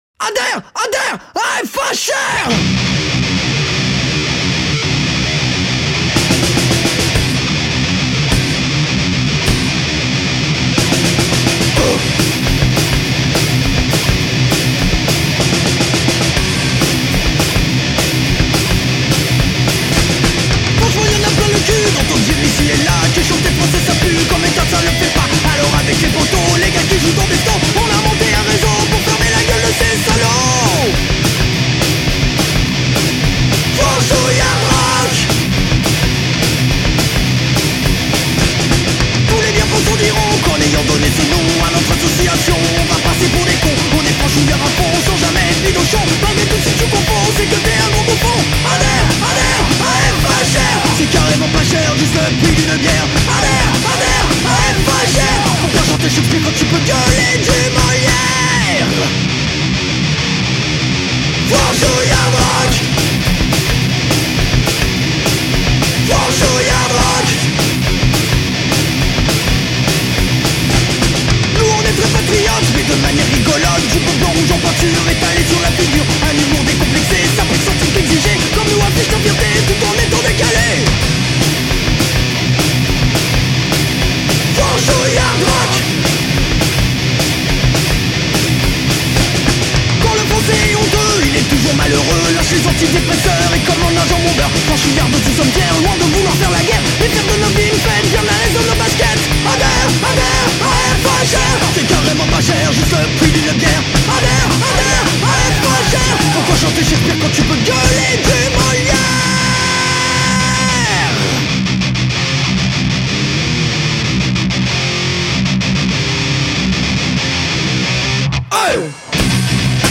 Franchoill Hard Rockl France